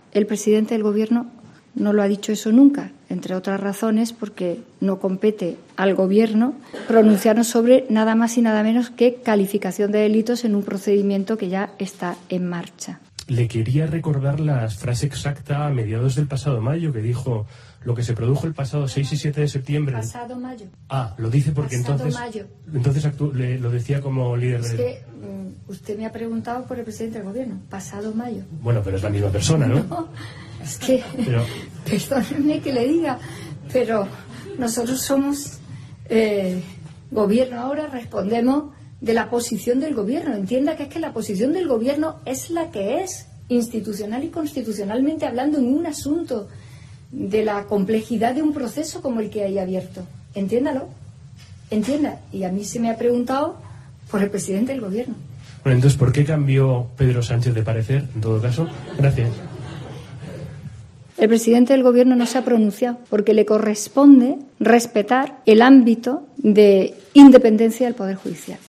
A la vicepresidenta del Gobierno Carmen Calvo también se le ha preguntado por qué Sánchez vio en el pasado un delito de rebelión en los actos del 1-O y no ahora.